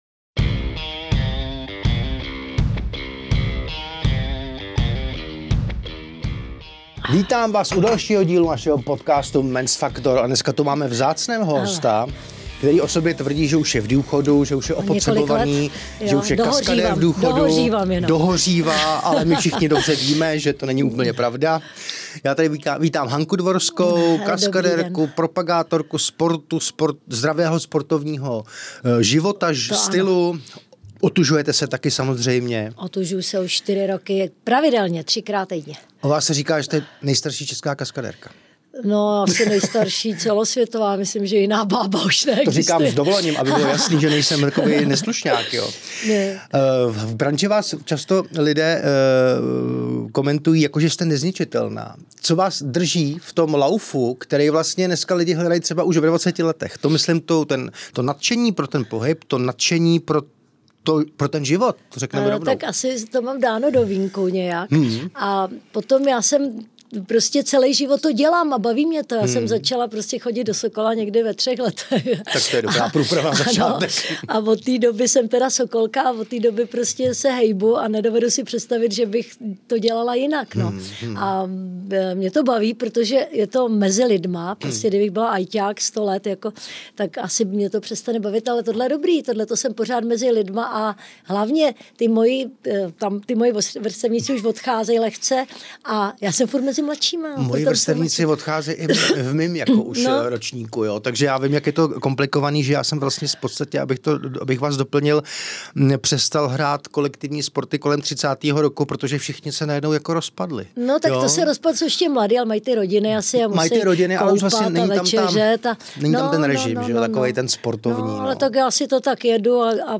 V rozhovoru otevřeně mluví o strachu, který zažila při prvním pádu z okna, o syrových podmínkách filmového kaskadérství před revolucí i o tom, jak zásadní roli hraje hlava.